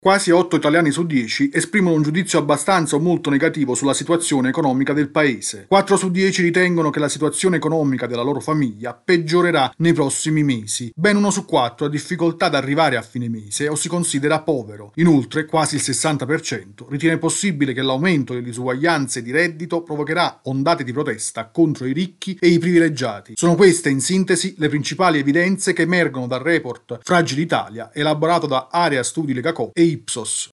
Alleanza contro la povertà esprime preoccupazione dopo la decisione del governo sul Reddito di cittadinanza. Il servizio